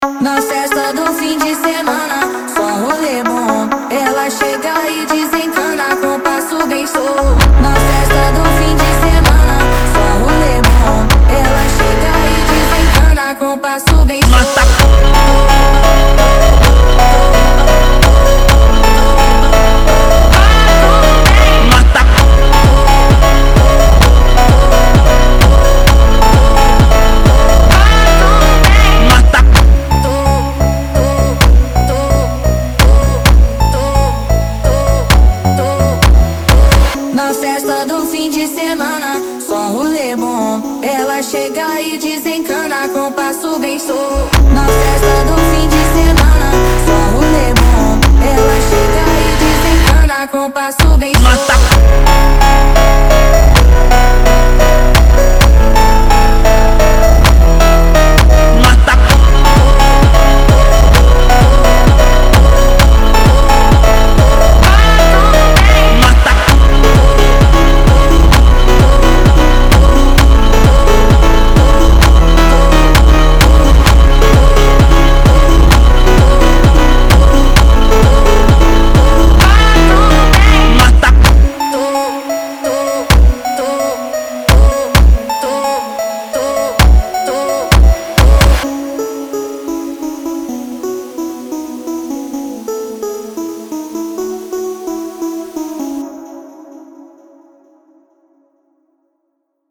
سبک ریمیکس